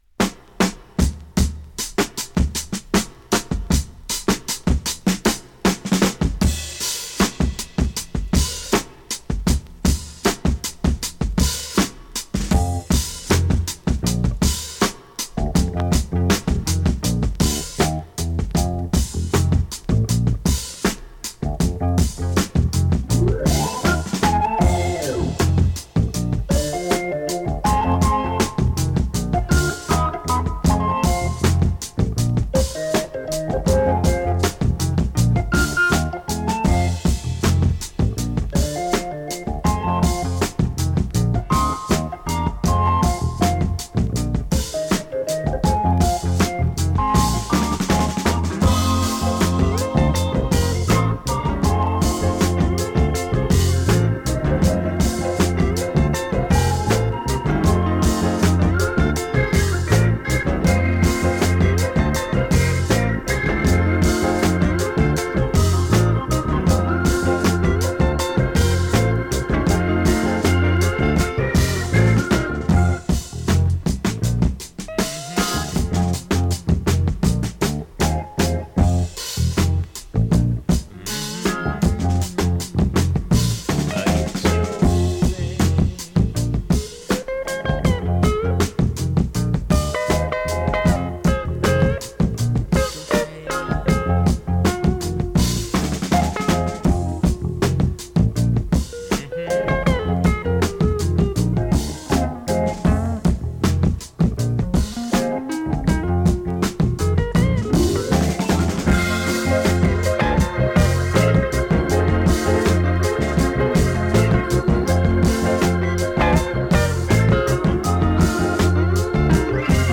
UK盤 Reissue 7"Single 45 RPM現物の試聴（両面すべて録音時間５分４５秒）できます。